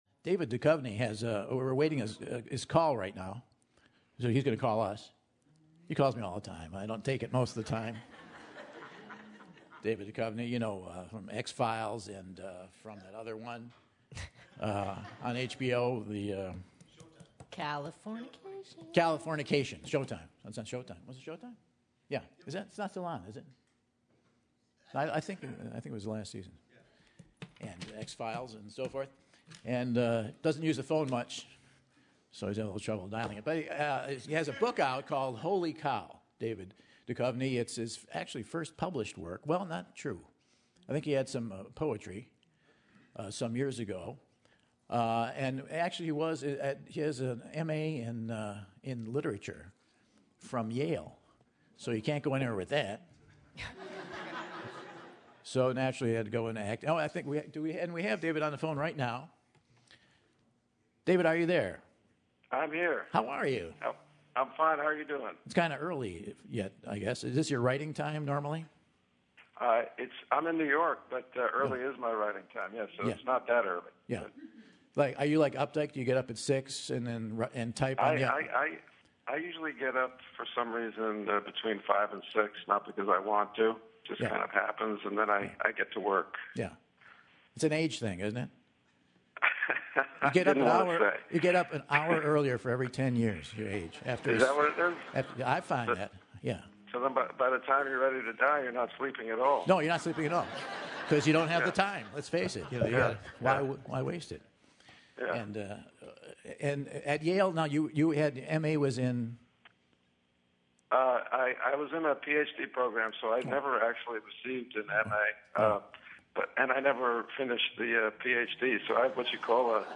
David Duchovny is best known for playing FBI Agent Fox Mulder on the science fiction horror drama series The X-Files but Duchovny's latest work is the book Holy Cow! This week Michael Dials him up and finds out more on the book and his other projects.